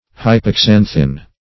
Search Result for " hypoxanthin" : The Collaborative International Dictionary of English v.0.48: Hypoxanthin \Hy`po*xan"thin\, n. [Pref. hypo- + xanthin.]
hypoxanthin.mp3